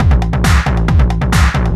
DS 136-BPM A6.wav